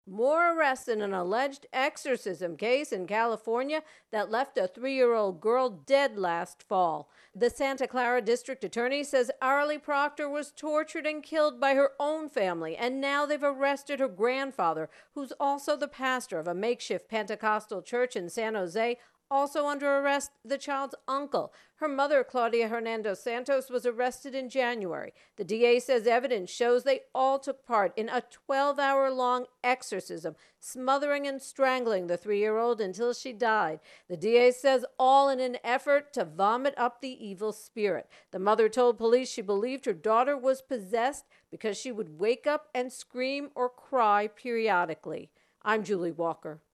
California Church Exorcism intro and voicer